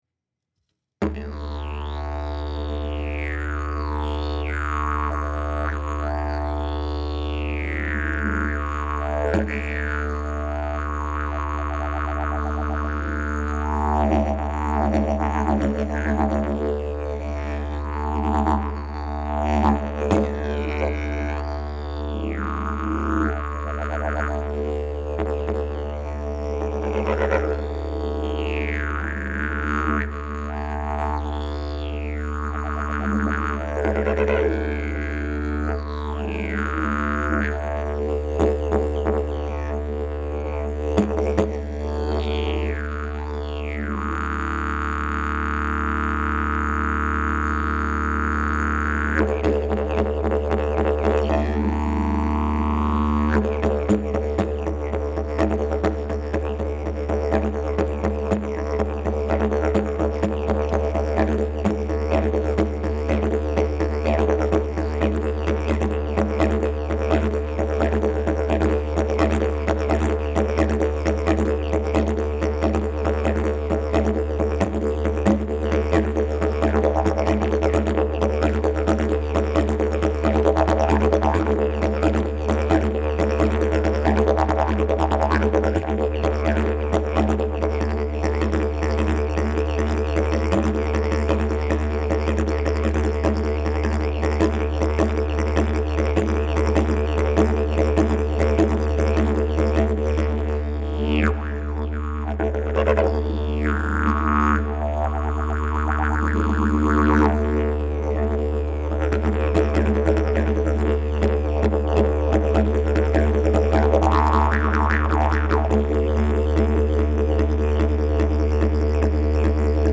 Key: D Length: 56" Bell: 5.75" Mouthpiece: Bloodwood, Red Zebrawood, Sapele Back pressure: Very strong Weight: 3lbs Skill level: Any
Didgeridoo #610 Key: D